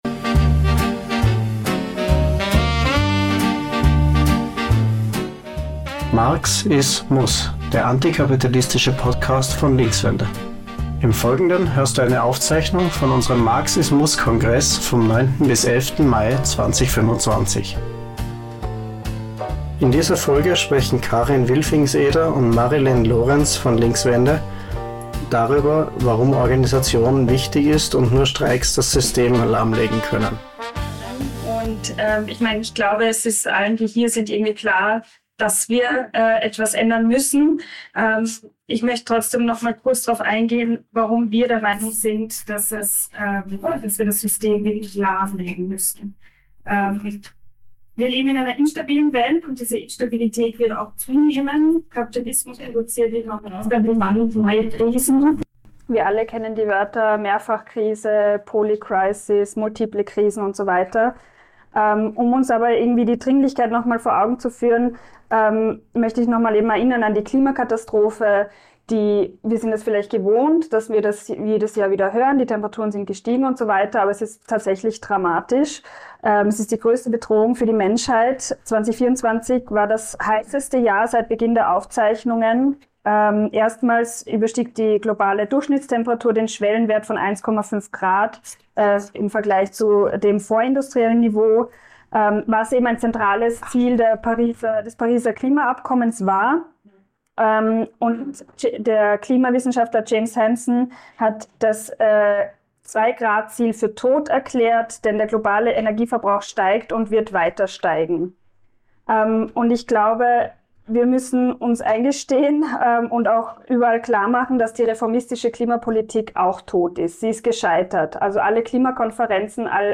Du hörst eine Aufzeichnung vom MARX IS MUSS Kongress von 09.-11. Mai 2025 in Wien.